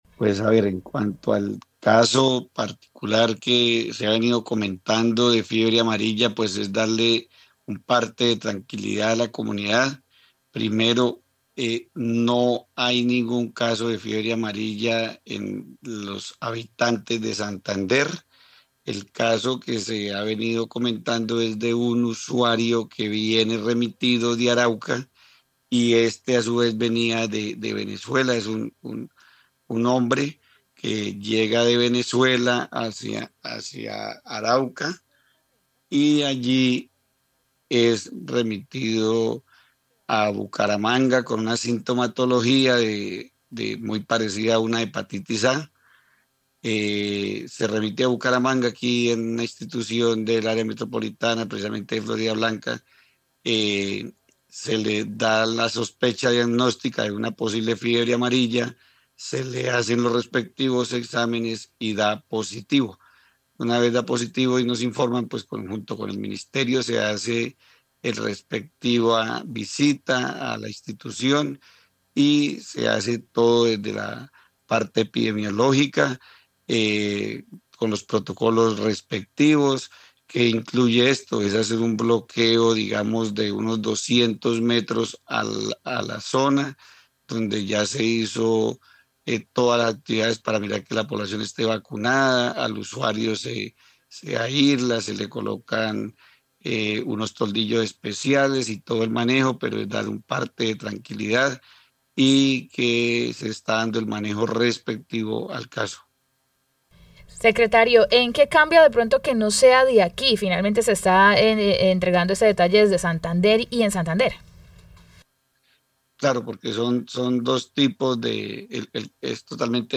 Edwin Prada, secretario de Salud de Santander